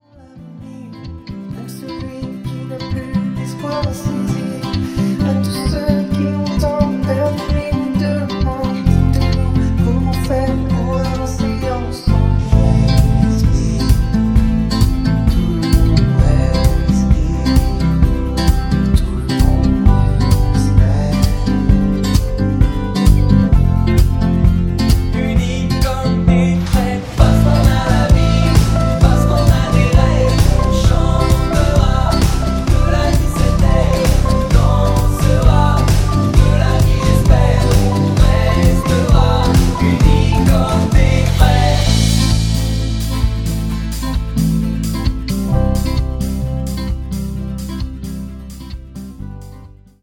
(avec choeurs)